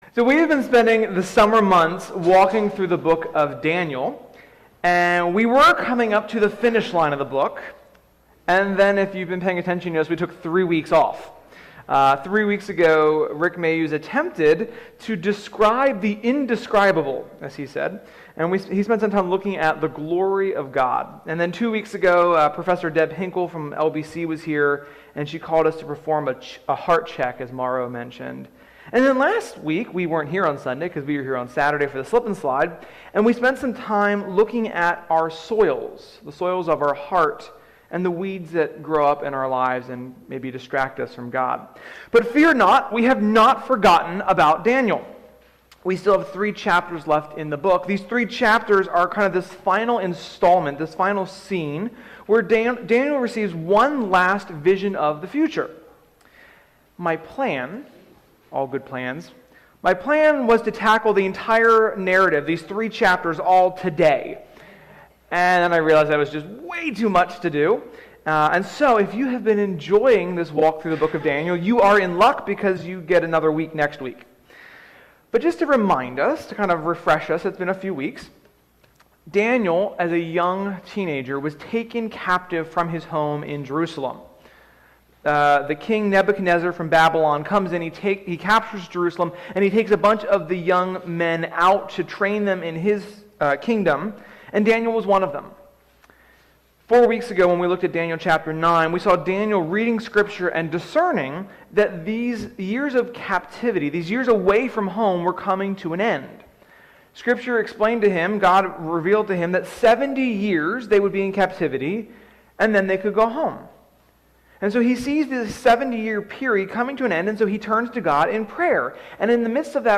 Sermon-9.5.21.mp3